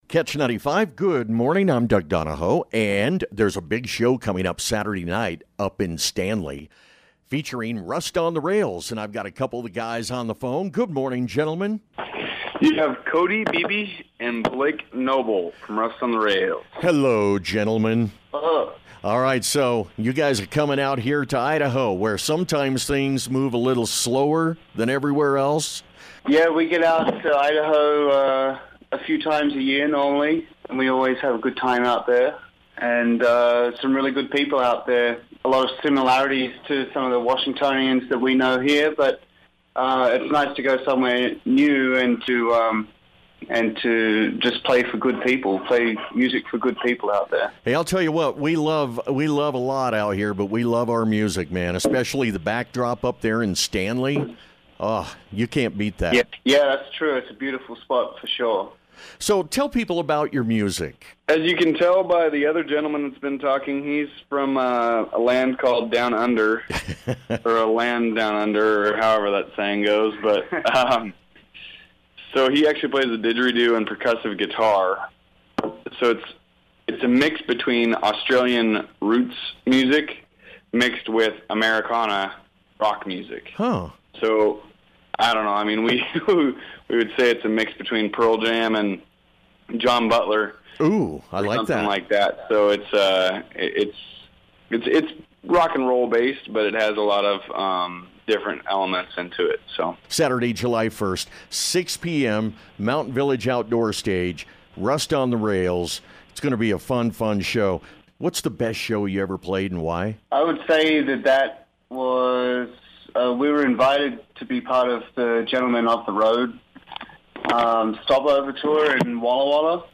KECH Interview w/Rust On The Rails